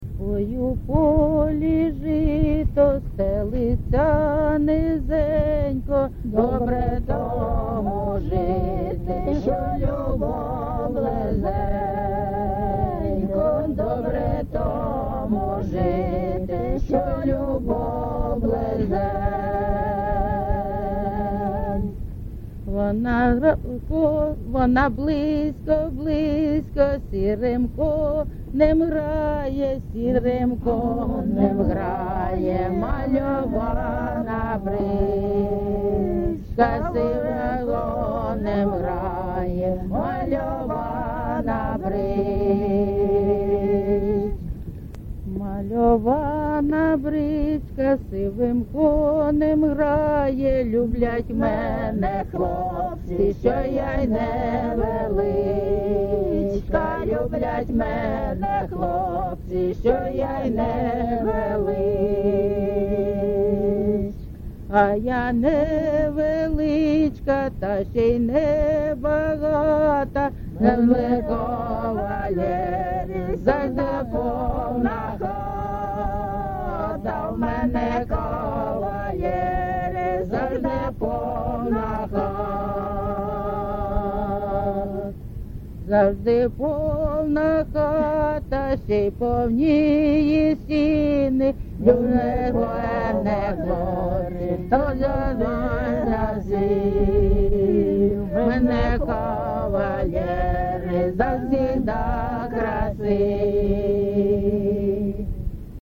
ЖанрПісні з особистого та родинного життя
Місце записус. Богородичне, Словʼянський район, Донецька обл., Україна, Слобожанщина